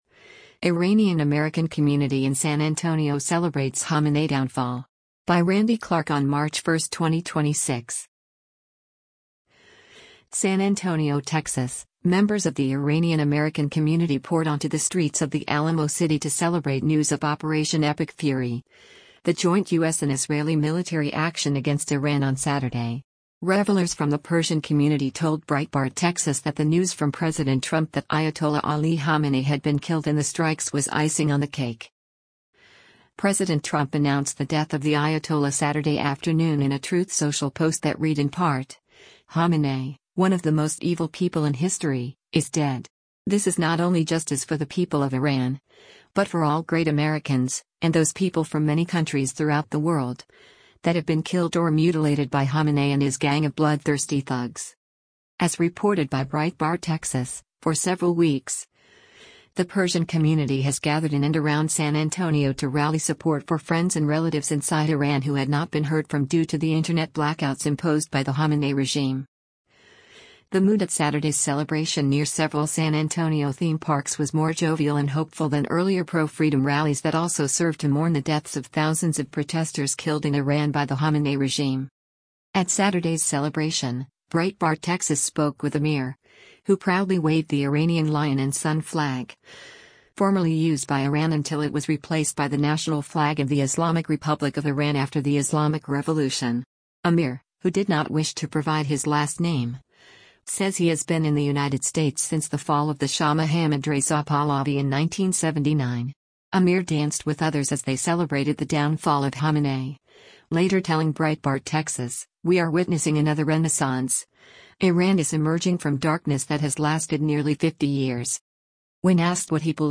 The mood at Saturday’s celebration near several San Antonio theme parks was more jovial and hopeful than earlier pro-freedom rallies that also served to mourn the deaths of thousands of protesters killed in Iran by the Khamenei regime.
The group marched towards a nearby theme park and continued the celebration, carrying signs that read “Make Iran Great Again” and others featuring images of Crown Prince Reza Pahlavi, the eldest son of the late Shah of Iran, as passing motorists honked their horns.